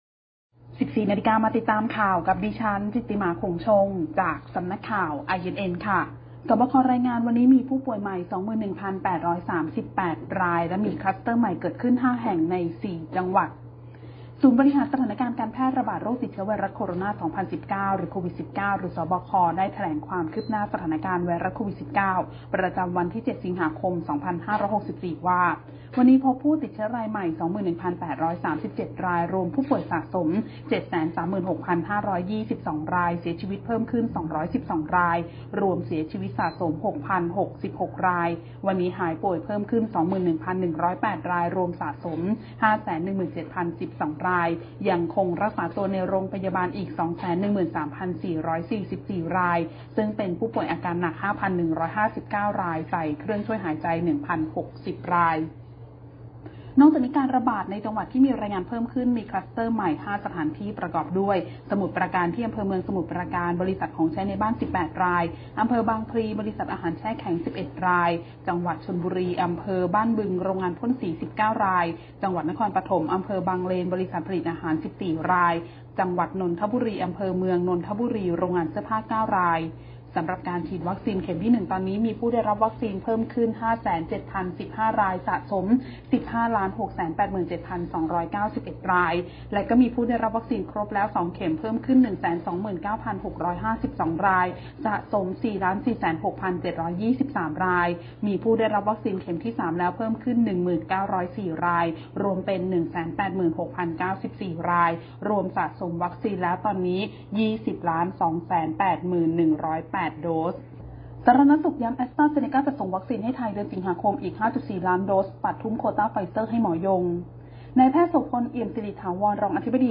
คลิปข่าวต้นชั่วโมง
ข่าวต้นชั่วโมง 14.00 น.